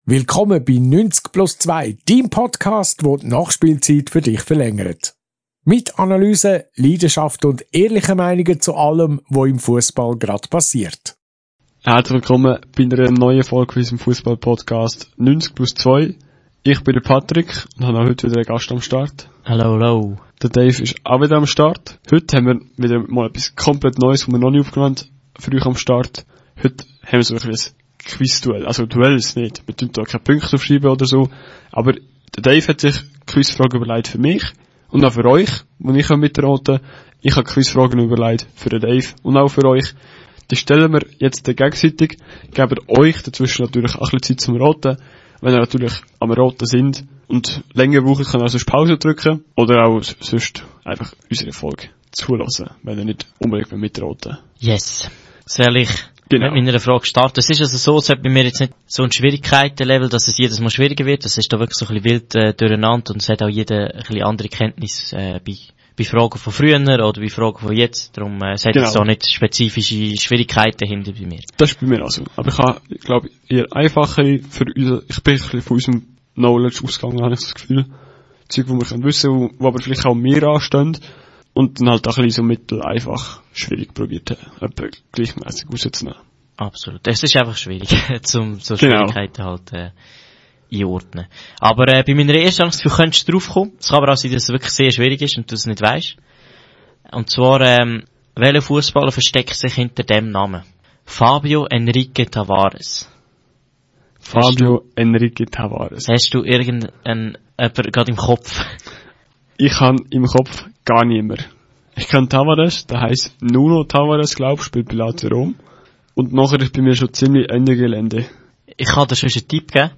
In einem unterhaltsamen Fußball-Quiz stellen wir unser Wissen auf die Probe und fordern uns gegenseitig mit spannenden, kniffligen und manchmal auch überraschenden Fragen heraus. Dabei nennen wir interessante Fakten aus der Fußballgeschichte, tauchen in legendäre Momente ein und suchen nach bekannten – und weniger bekannten – Spitznamen von Spielern, Vereinen und Mannschaften.